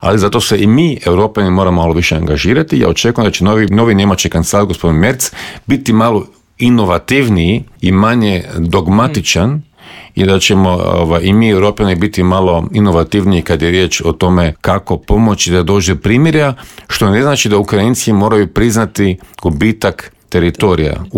ZAGREB - Aktualne teme s naglaskom na početak konklave, izbor novog pape, ratne sukobe i situaciju u susjedstvu, prokomentirali smo u Intervjuu Media servisa s diplomatom i bivšim ministarom vanjskih poslova Mirom Kovačem.